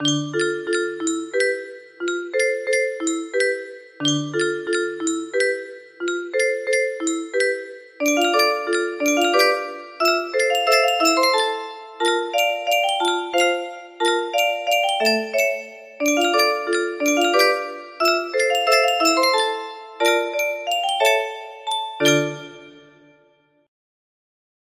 Fixed chords in the middle part.